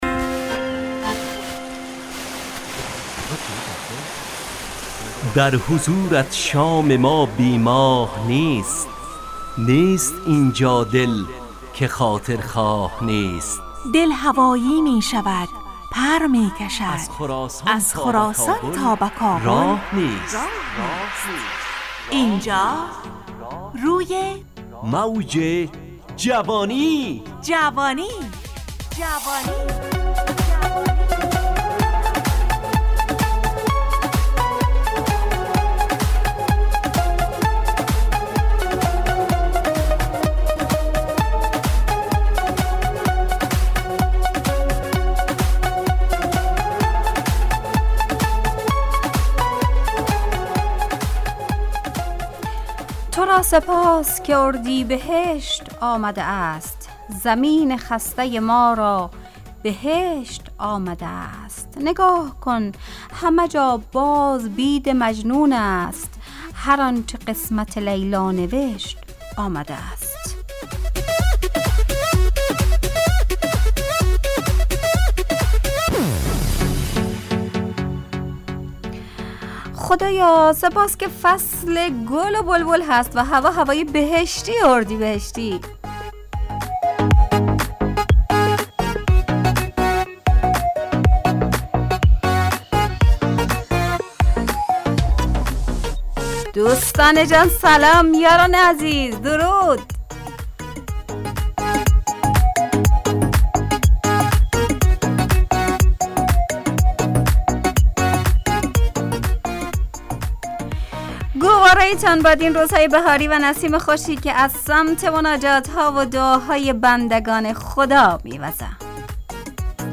همراه با ترانه و موسیقی .